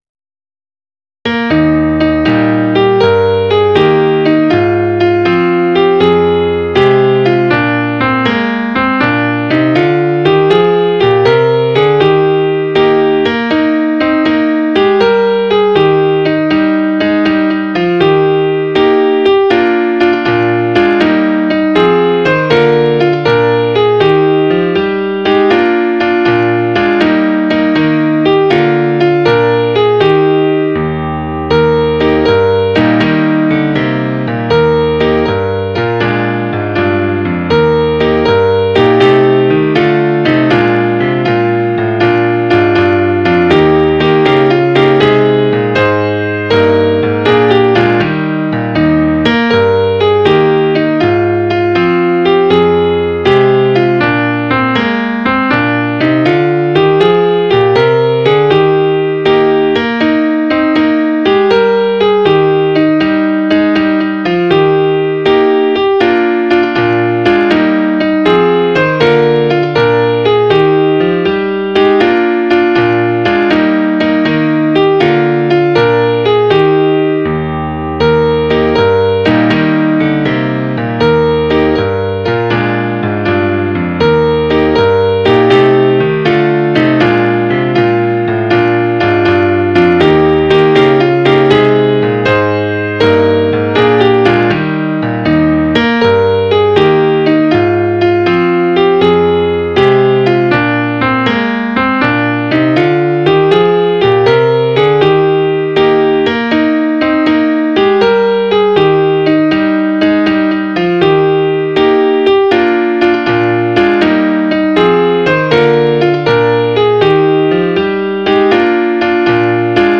Midi File, Lyrics and Information to Eight Hours